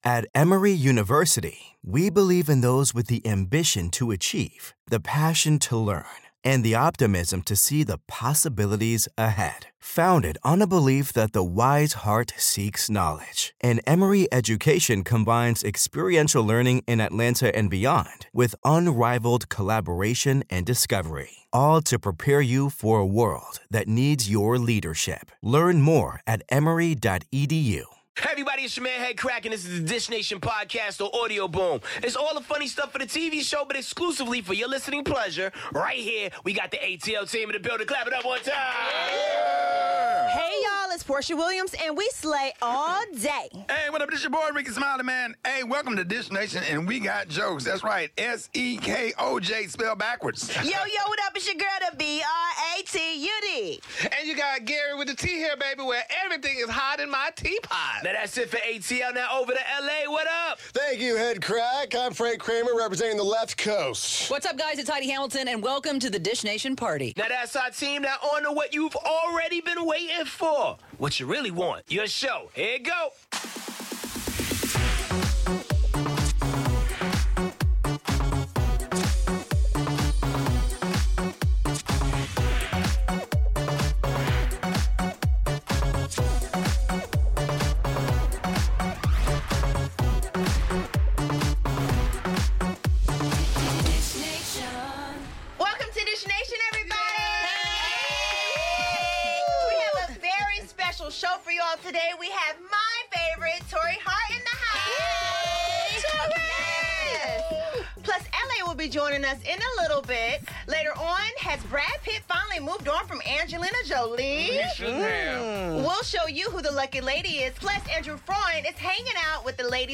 Khloe Kardashian gives birth & we talk with Anna Faris and Allison Janney about their hit show 'Mom.'